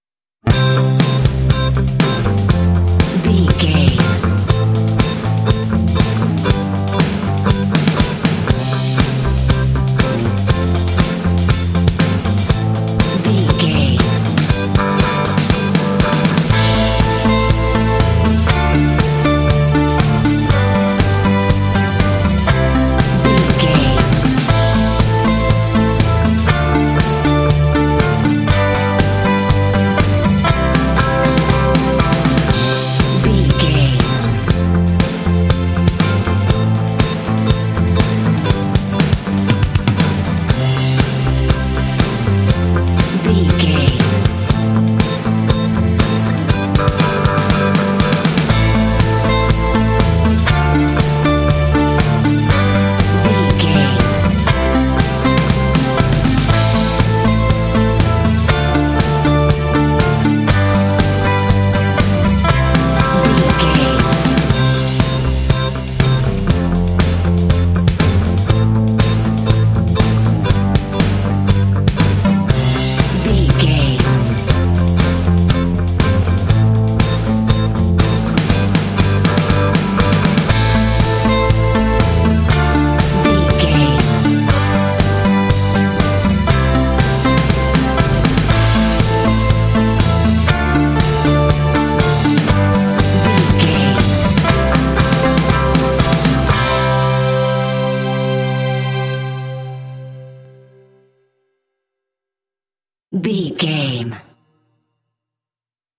Uplifting
Ionian/Major
bouncy
cheerful/happy
drums
bass guitar
synthesiser
electric guitar
strings
pop
alternative rock